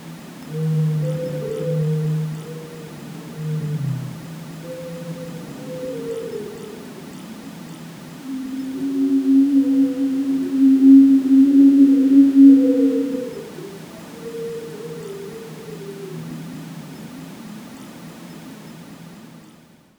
Scene opening Gentle distant wind One or two faint crows in the distance Around the mansion Slight metallic creak of an iron gate Shimmering glass tone when windows are mentioned Emotional layer Hollow room echo (to show lack of laughter)
scene-openinggentle-dista-yz633fe6.wav